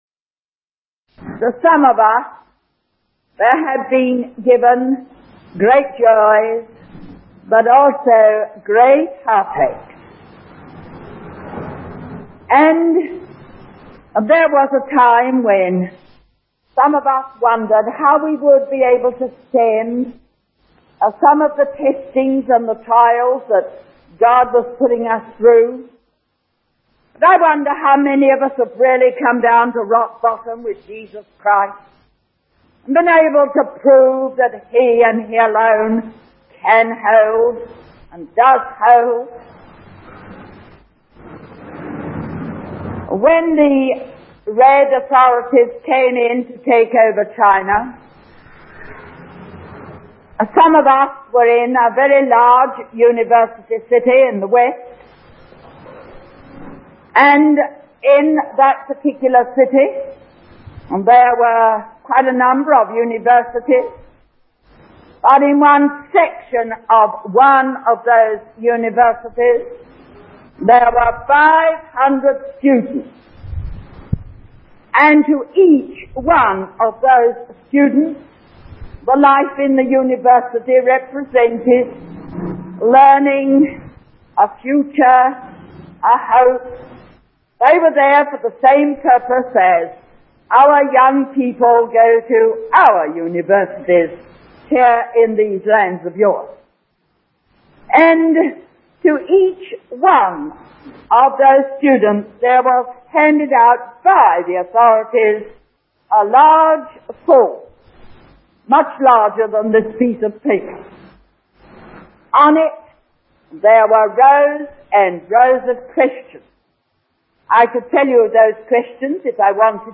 The sermon challenges listeners to reflect on their own convictions and the necessity of proving their faith in a world that often opposes it.